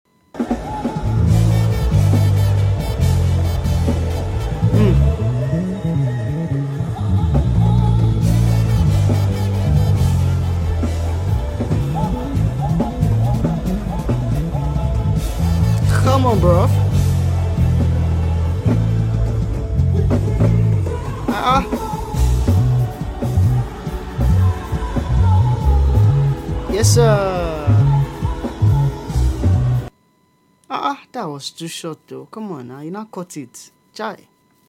Bass Player Tryna Take Over Sound Effects Free Download